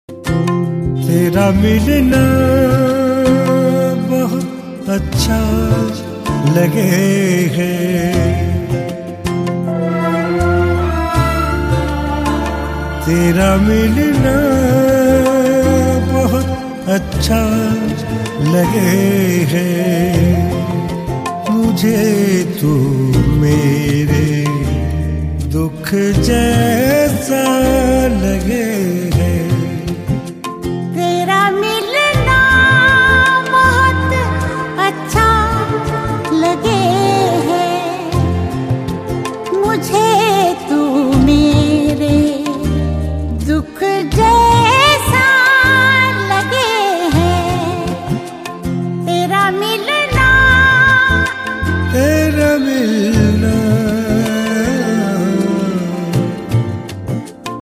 File Type : Indian pop